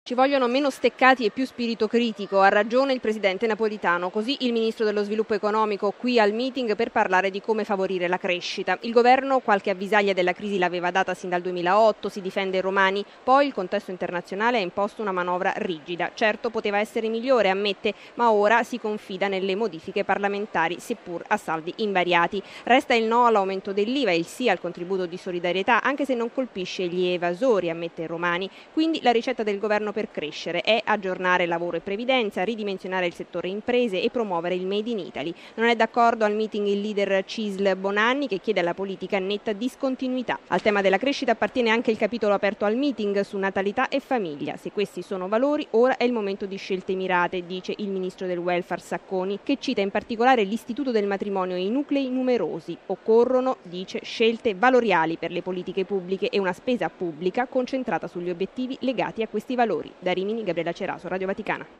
In particolare si è parlato di crescita, di natalità e famiglia, del sistema Paese e in tarda serata si affronterà anche il tema della dipendenza italiana dall’Unione europea. Il servizio della nostra inviata